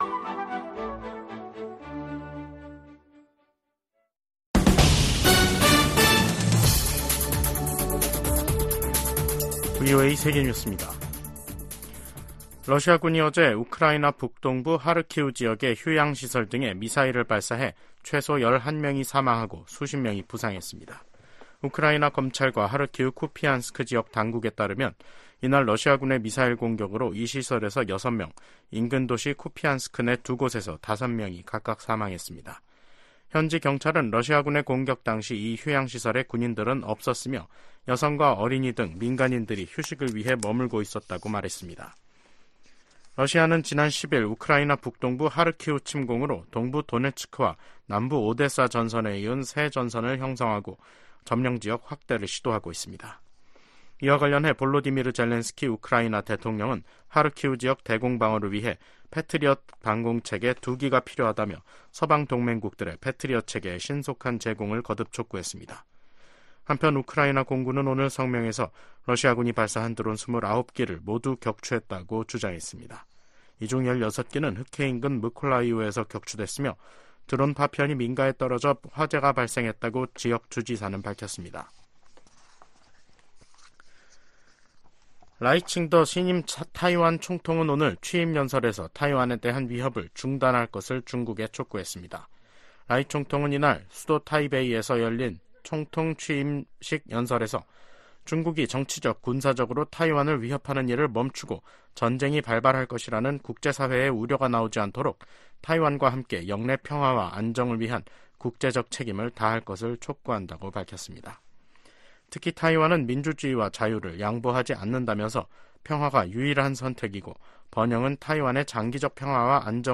VOA 한국어 간판 뉴스 프로그램 '뉴스 투데이', 2024년 5월 20일 2부 방송입니다. 미국 국무부가 북한의 단거리 탄도미사일 발사를 규탄하며 거듭되는 북한 미사일 발사의 불법성을 지적했습니다. 북한과 러시아가 대량살상무기 관련 불법 금융활동 분야에서 가장 큰 위협국이라고 미국 재무부가 밝혔습니다. 미국, 한국, 일본의 협력 강화를 독려하는 결의안이 미국 하원 외교위원회를 통과했습니다.